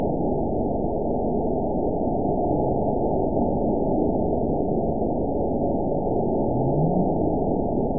event 916941 date 02/20/23 time 17:34:17 GMT (2 years, 2 months ago) score 9.56 location TSS-AB01 detected by nrw target species NRW annotations +NRW Spectrogram: Frequency (kHz) vs. Time (s) audio not available .wav